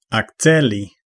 Ääntäminen
US : IPA : [əkˈsel.ə.reɪt]